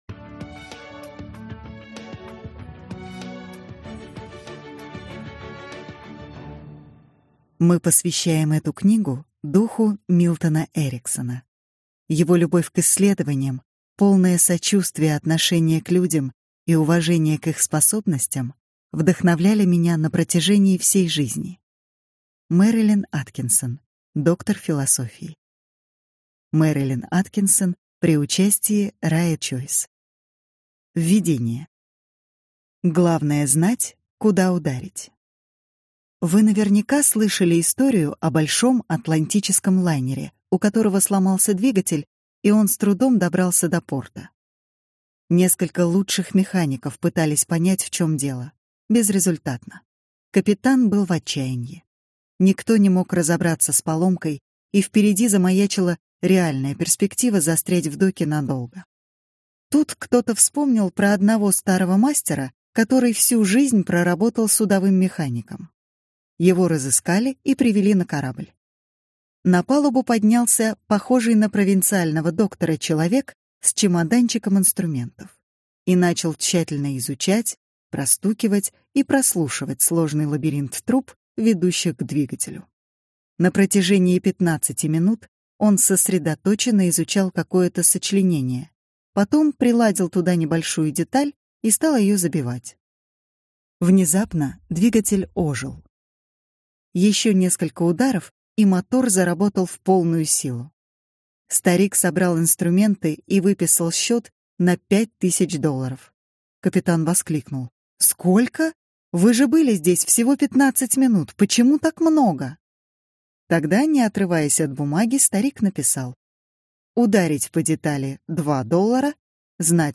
Аудиокнига Мастерство жизни. Внутренняя динамика развития | Библиотека аудиокниг